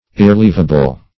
Irrelievable \Ir`re*liev"a*ble\
irrelievable.mp3